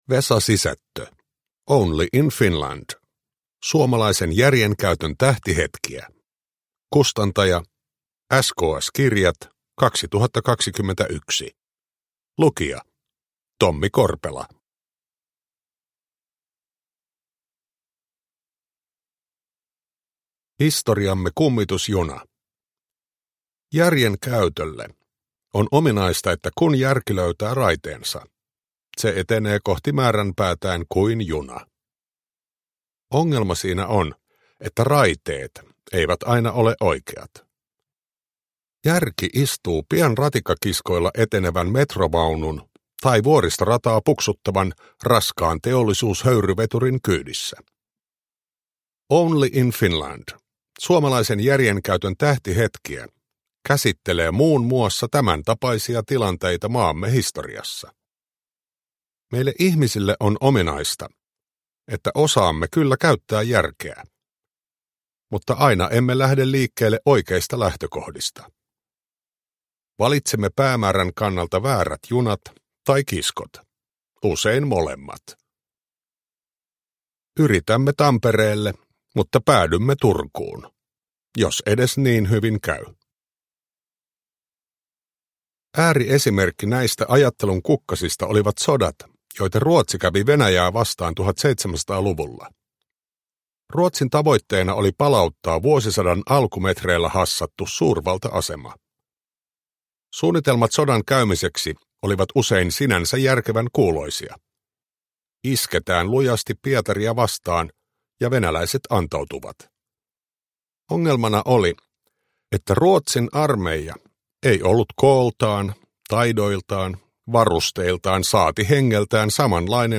Only in Finland – Ljudbok – Laddas ner
Uppläsare: Tommi Korpela